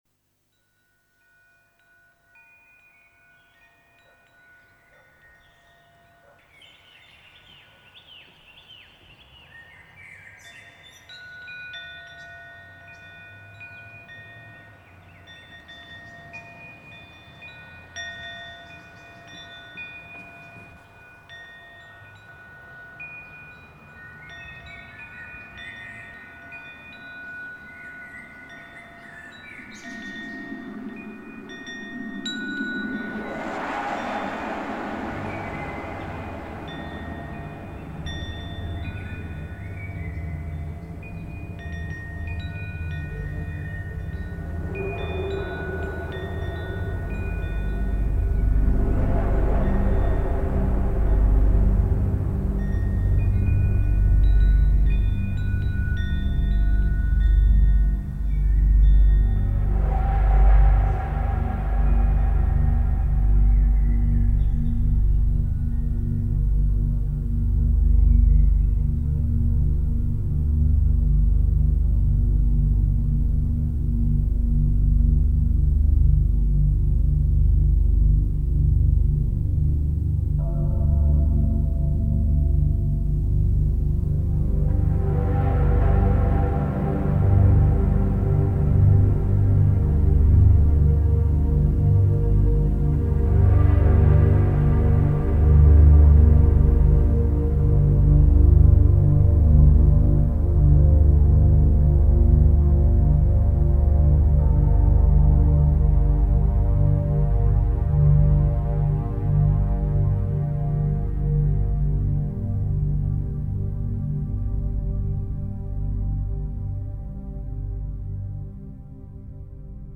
ambient music